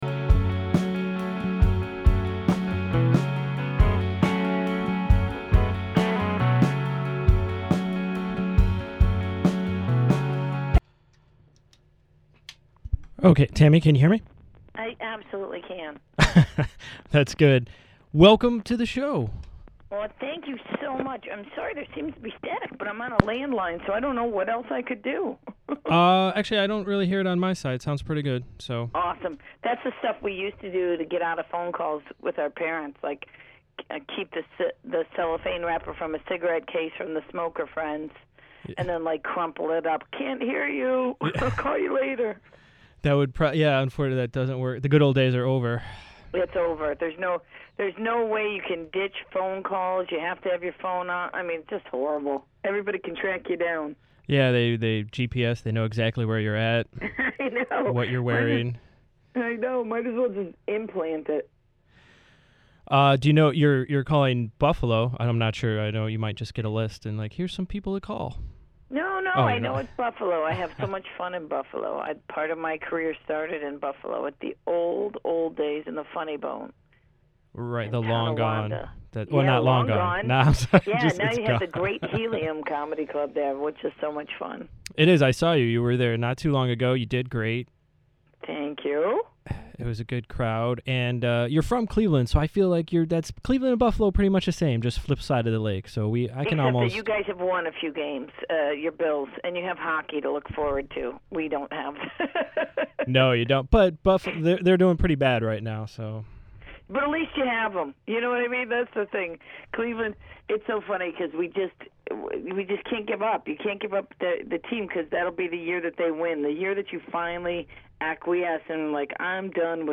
Tammy Pescatelli interview